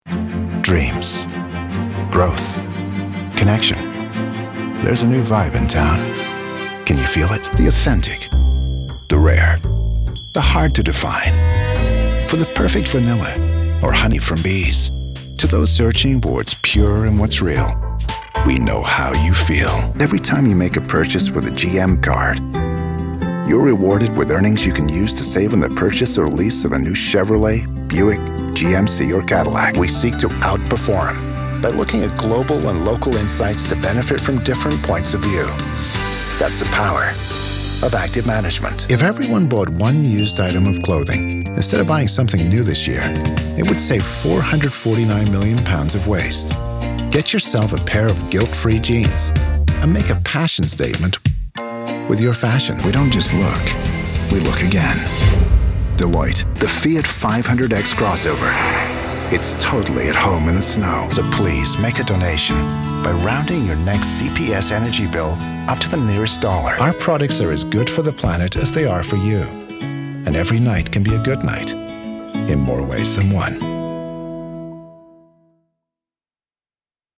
Inglés (Americano)
Natural, Llamativo, Cool, Amable, Empresarial
Comercial